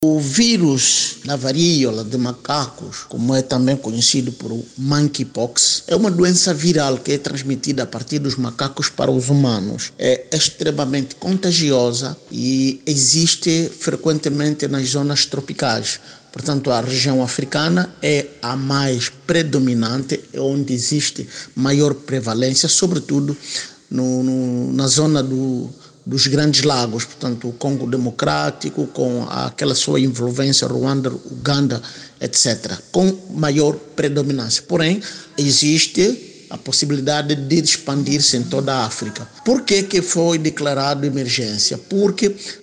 Aos microfones da RÁDIO NOVA, o especialista explicou o que é a doença.